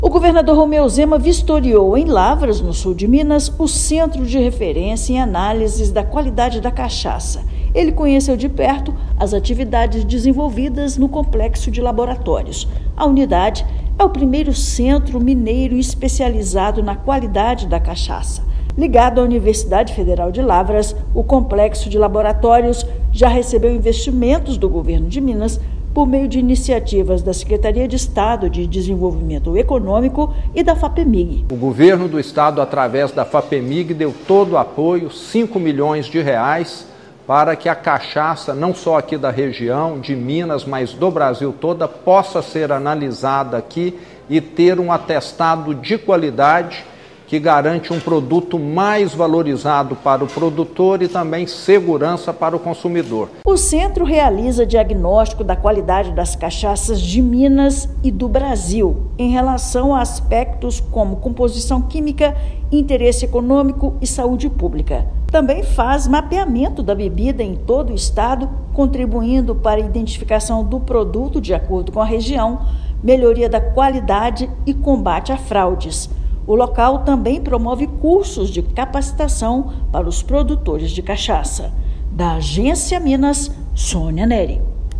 Laboratório já recebeu mais de R$ 4,4 milhões em investimentos por meio da Sede-MG e da Fapemig. Ouça matéria de rádio.